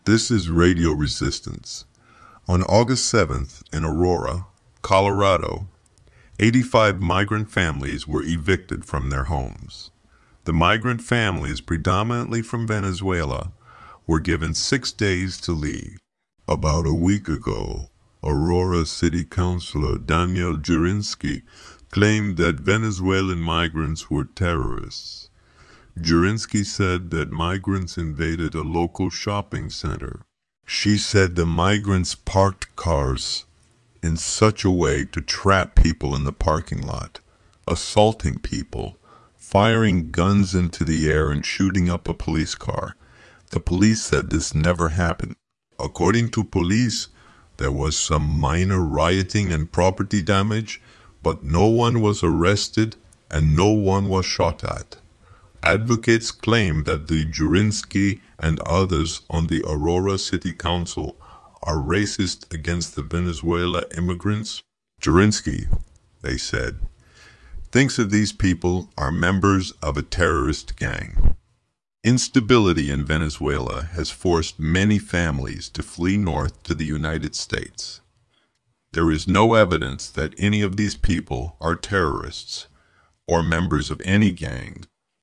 I'm using my own voice to train ai, but somehow it's picking up like several different accents.